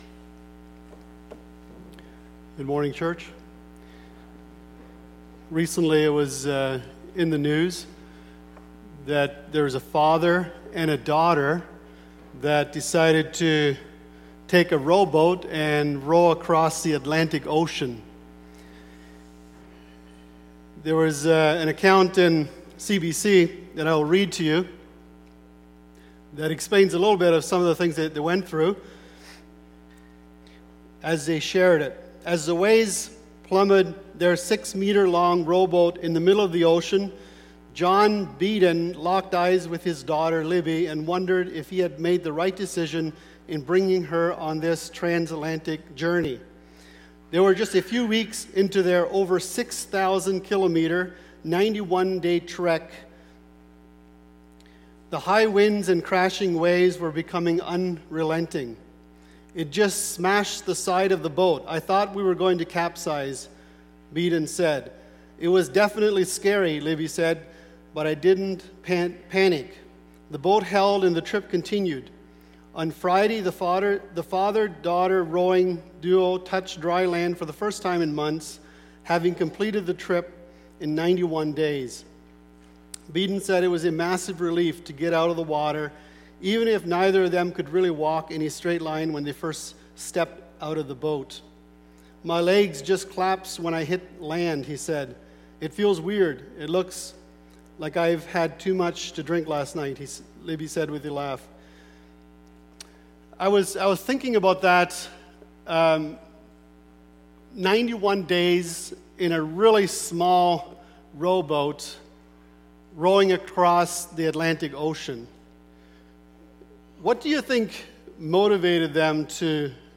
Sunday Morning Sermon Service Type